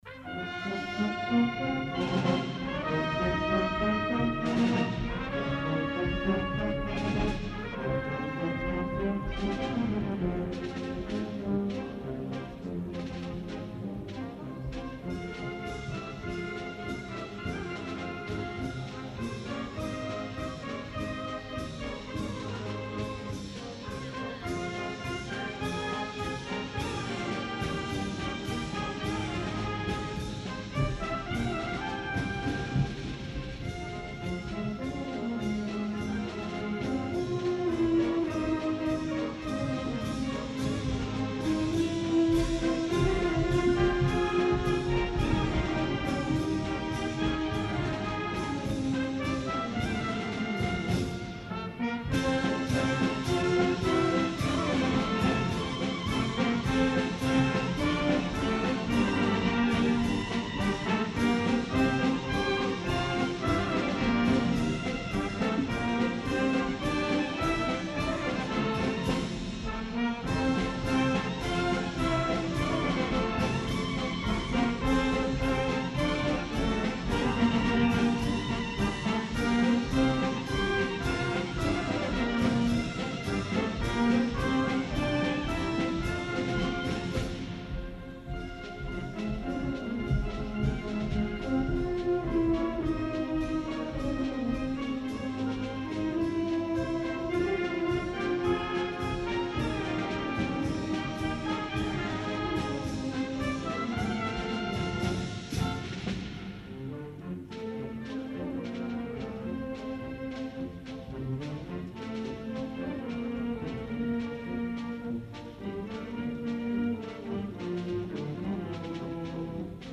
Concert de Santa Cecília a l'Esglèsia de la Nostra Senyora de la Consolació